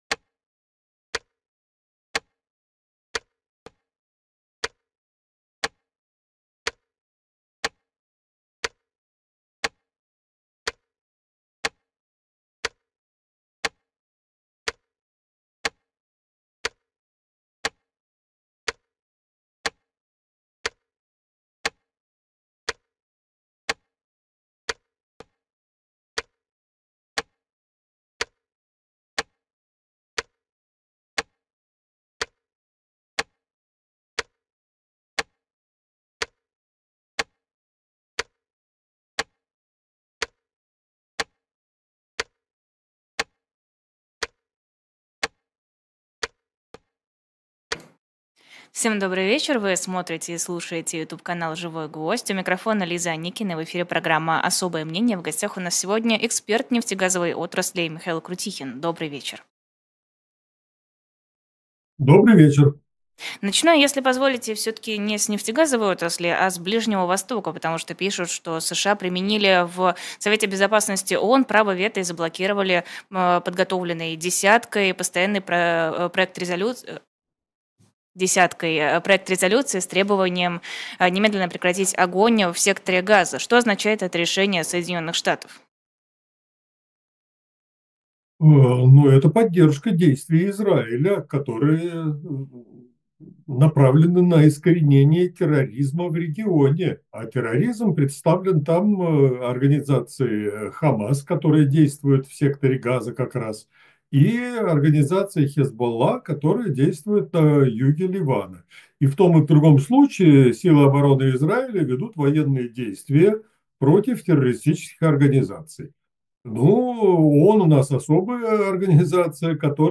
эксперт в сфере энергетики